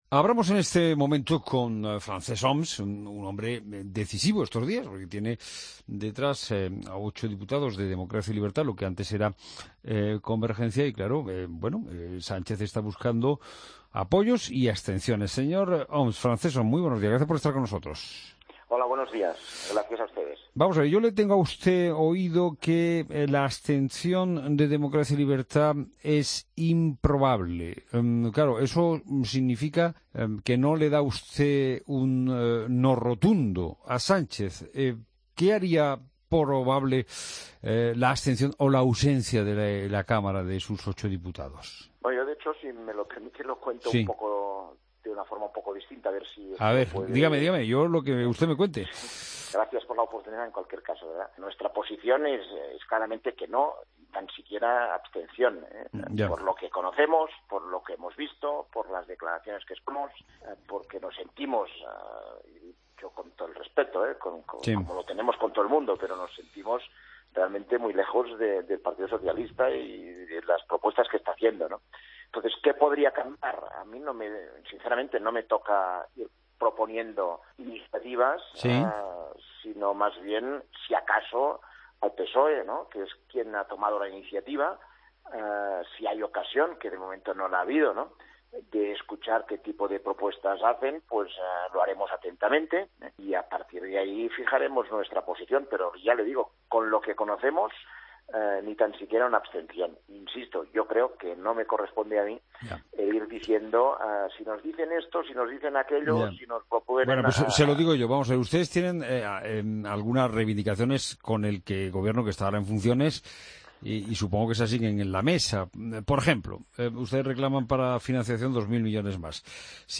Entrevista a Francesc Homs (DiL) en La Mañana del Fin de Semana